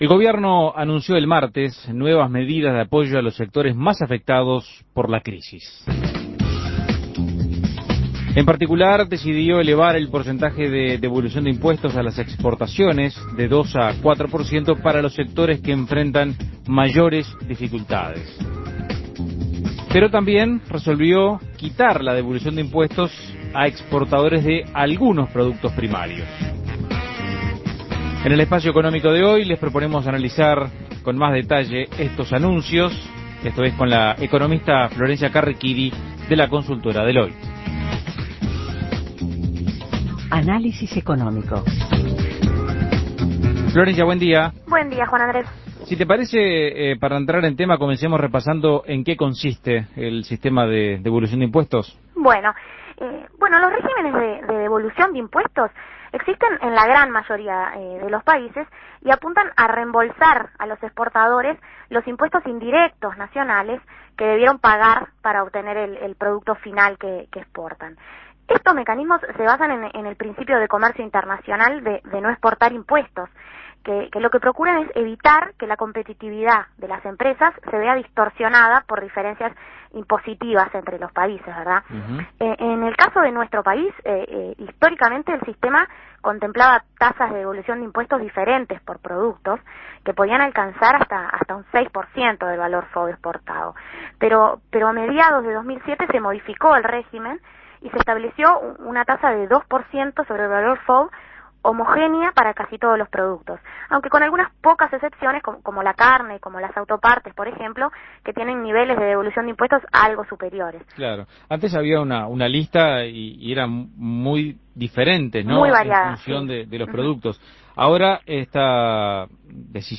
Análisis Económico ¿En qué consisten los cambios en el sistema de devolución de impuestos a las exportaciones que anunció el gabinete productivo?